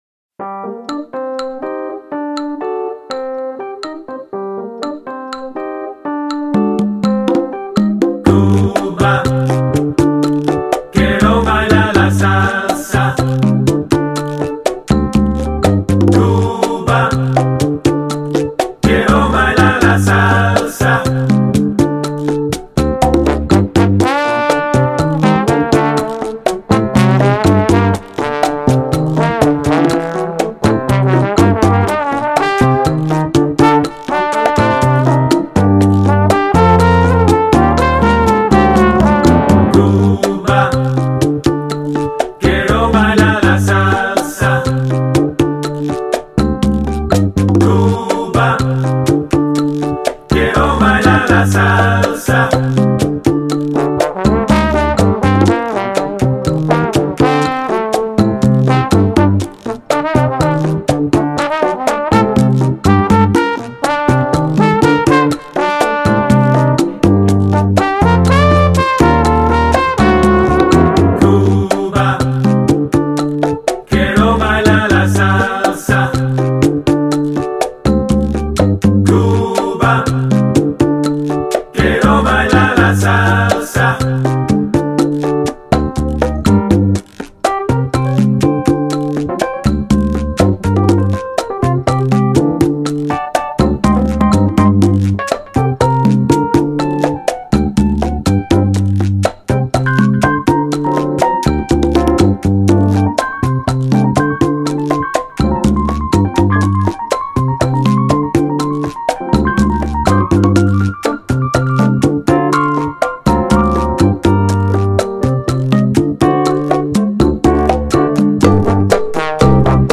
Keys/Vocals, Trombone/Vocals, Percussion/Vocals